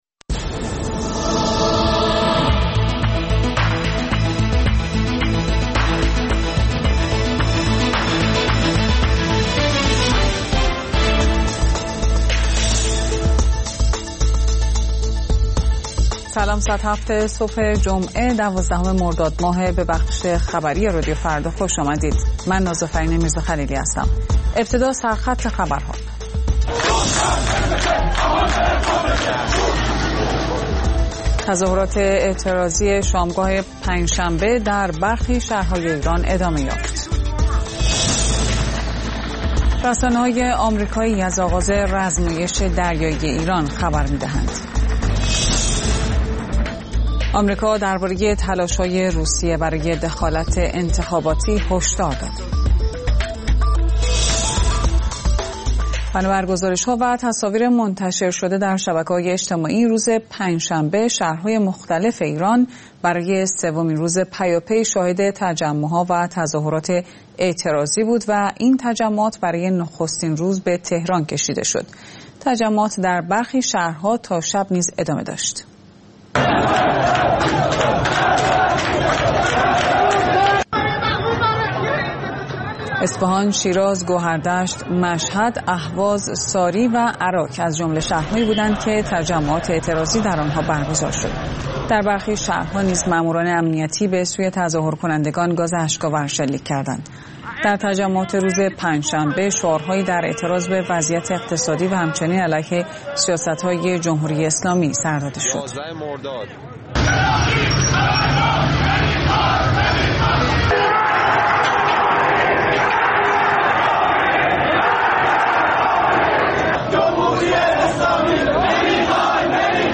گزارشگران راديو فردا از سراسر جهان، با تازه‌ترين خبرها و گزارش‌ها، مجله‌ای رنگارنگ را برای شما تدارک می‌بينند. با مجله بامدادی راديو فردا، شما در آغاز روز خود، از آخرين رويدادها آگاه می‌شويد.